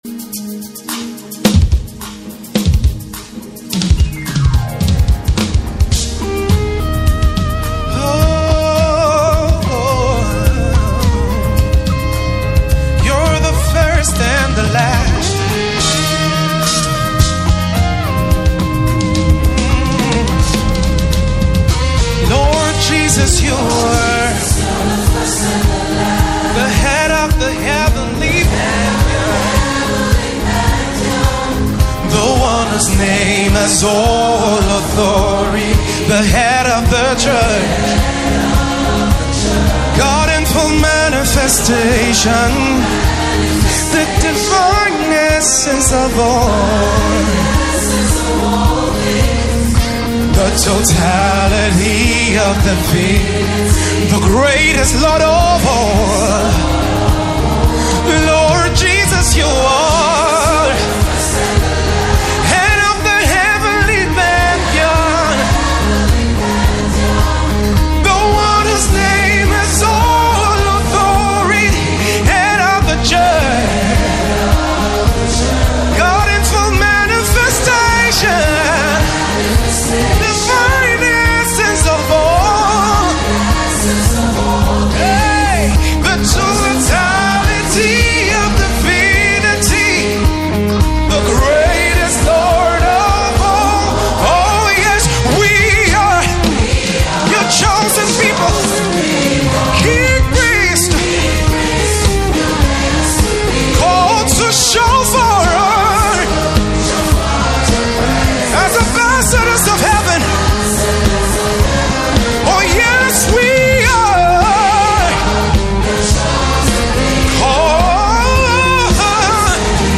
Key A-flat